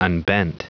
Prononciation du mot unbent en anglais (fichier audio)
Prononciation du mot : unbent